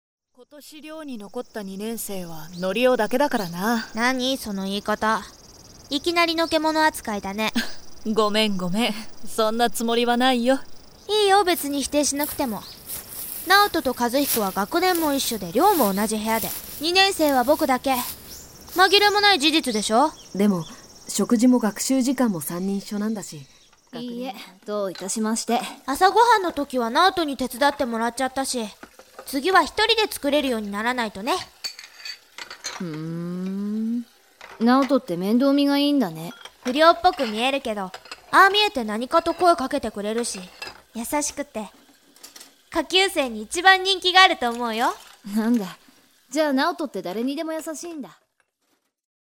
口調 子供らしい